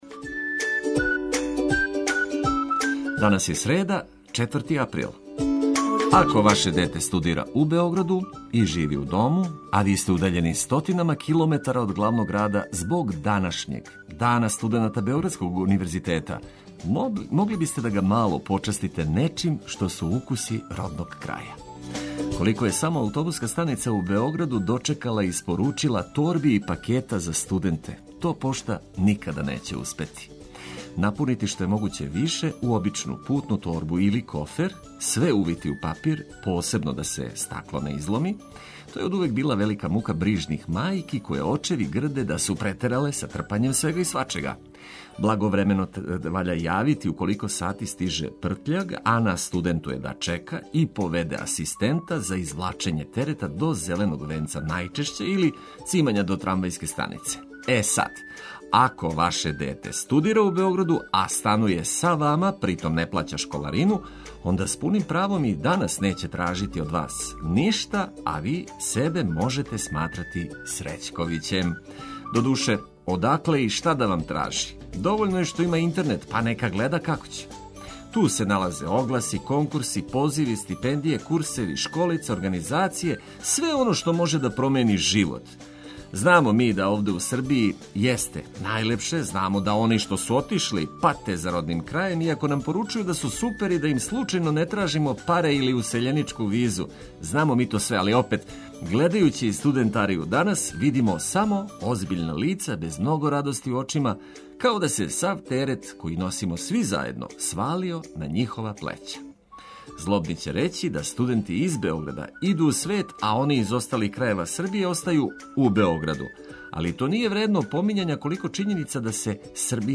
Све информације уз изабрану музику за буђење су - на једном месту.
Јутро је лепше уз одлично расположене радио пријатеље који ће вас пробудити уз много лепих нота, али и важних вести из земље и света.